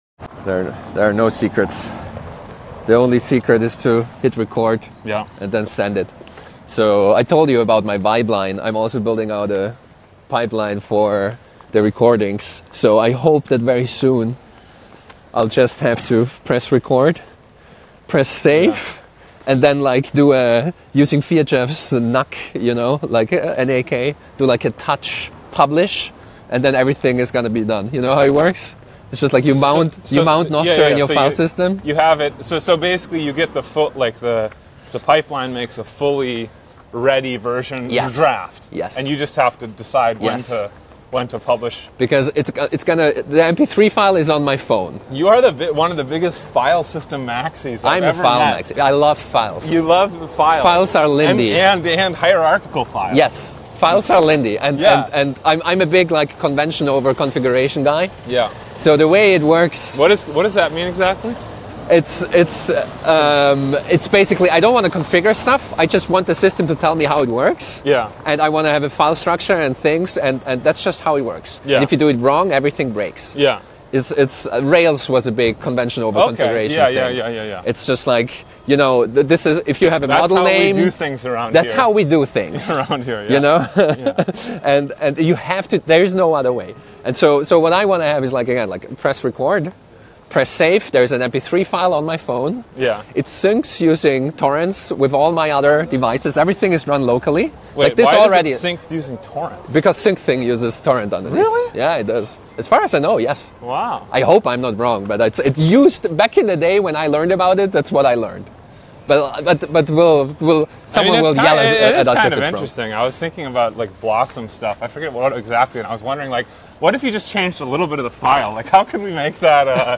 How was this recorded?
A walking dialog on what the right trade-off balance might be.